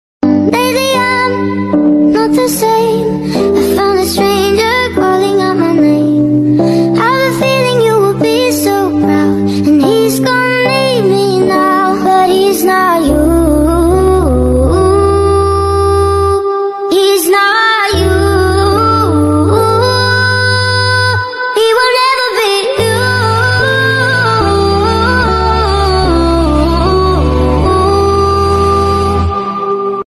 Honda jazz RS Gk5, Trouble sound effects free download